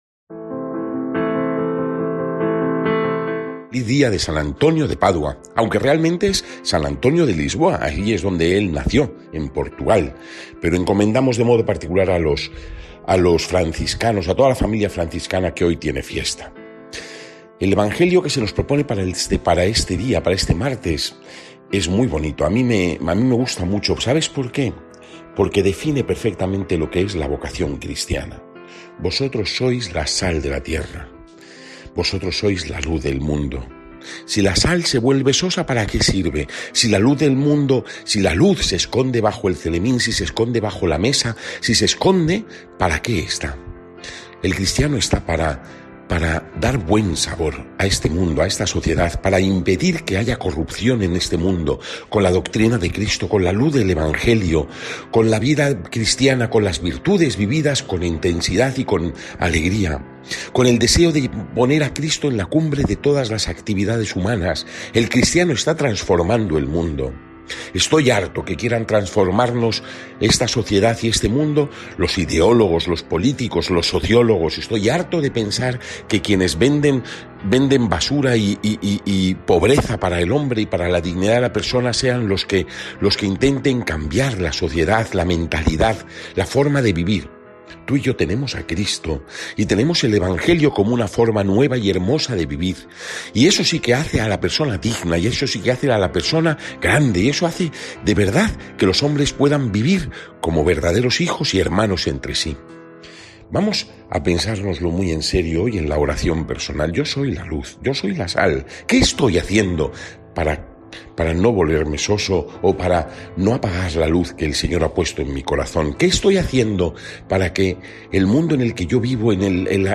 Evangelio según san Mateo (5, 13-16) y comentario